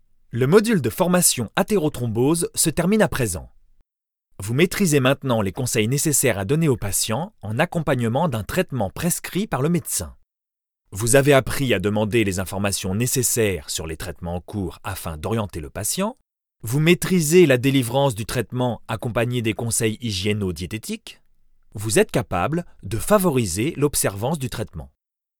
Elearning : voix medium posée